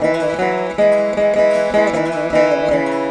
154A VEENA.wav